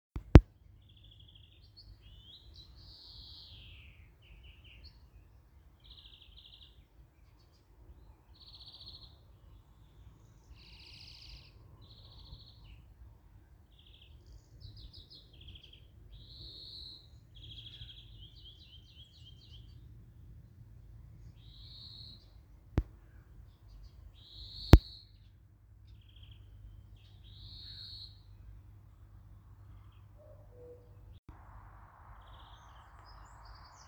Птицы -> Вьюрковые -> 1
зеленушка, Chloris chloris
СтатусПоёт